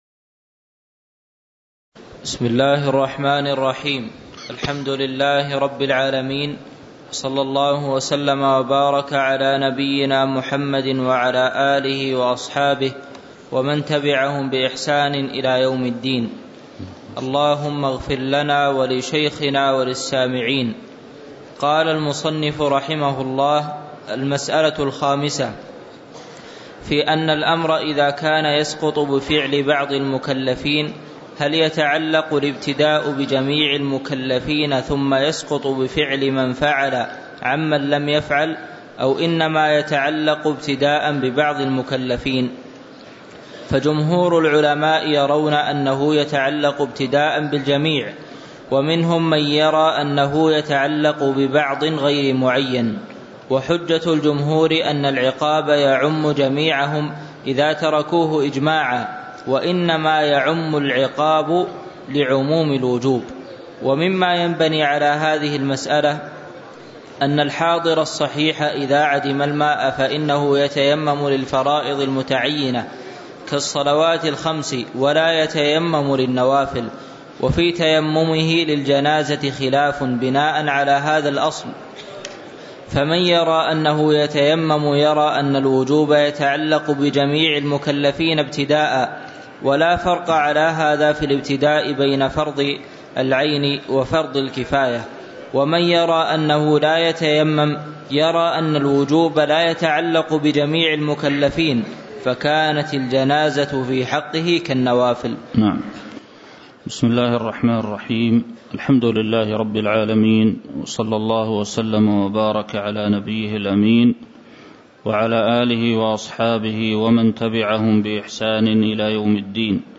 تاريخ النشر ١٤ جمادى الأولى ١٤٤١ هـ المكان: المسجد النبوي الشيخ